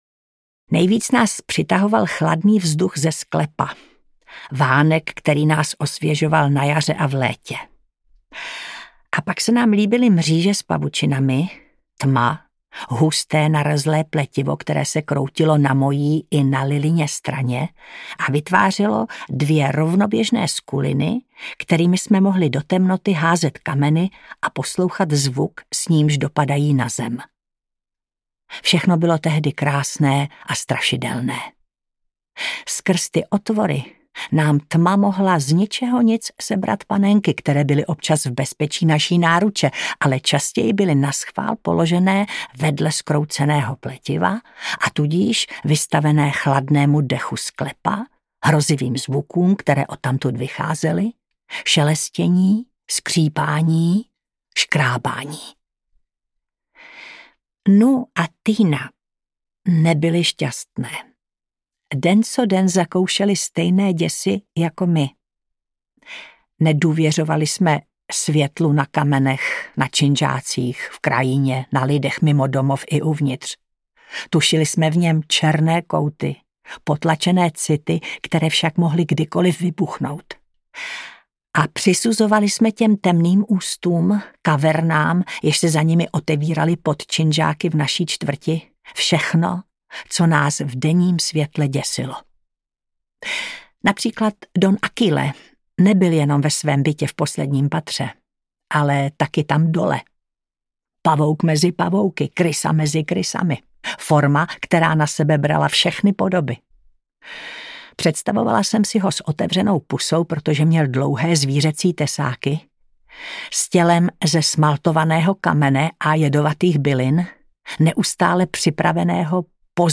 Vypočujte si ukážku audioknihy